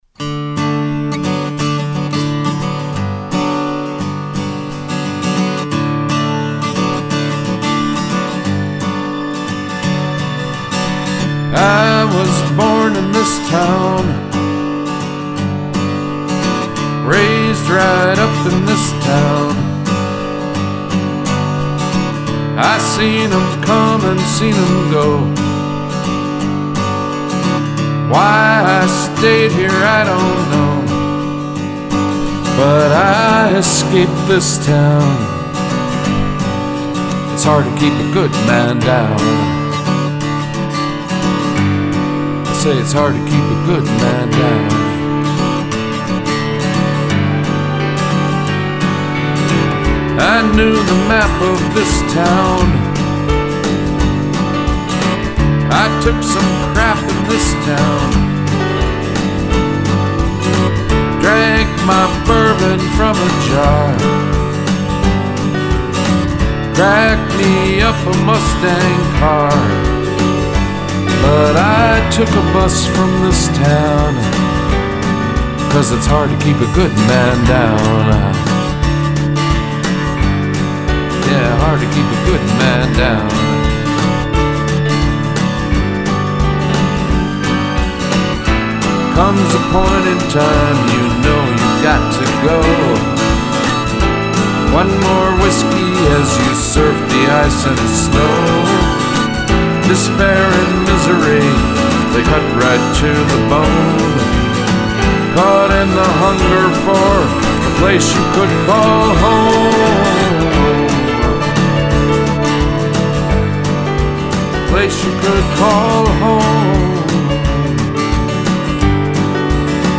Country, Traditional Americana, Soul, Reggae, Rock and miscellaneous